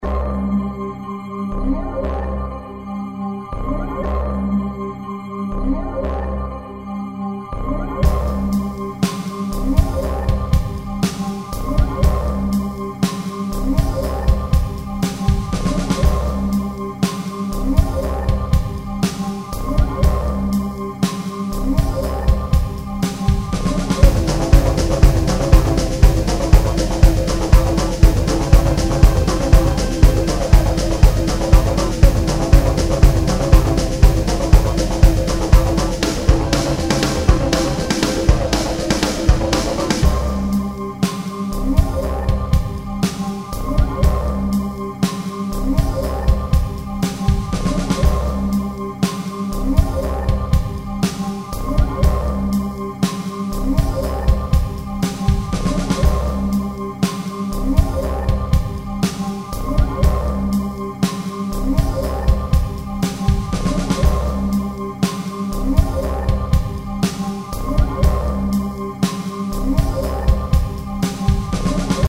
Song I made that sounded suitable for a cave or a dark area.